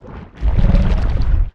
Sfx_creature_spikeytrap_bury_01.ogg